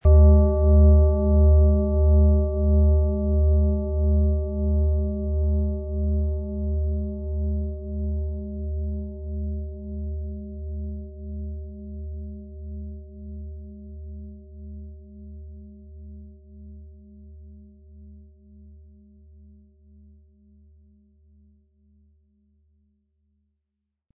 Planetenklangschale Saturn Frequenz: 147,85 Hz
Planetenklangschale: Saturn